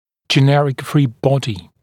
[ʤɪ’nerɪk friː ‘bɔdɪ][джи’нэрик фри: ‘боди]произвольное свободное тело